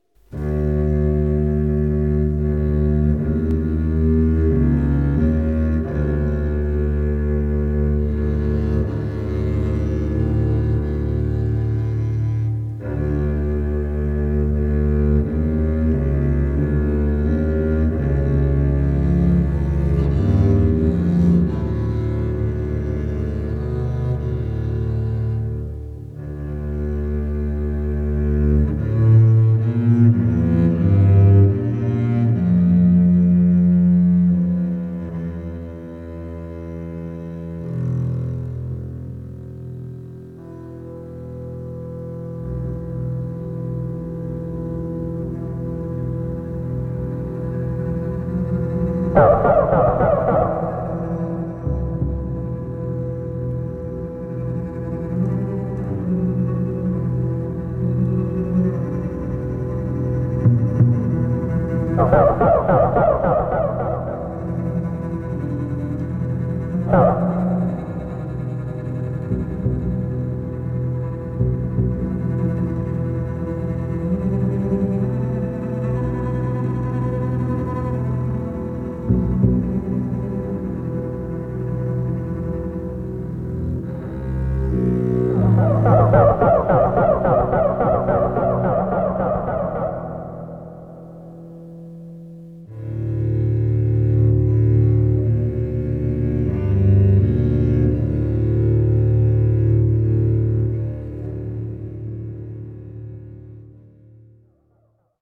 Incidental Music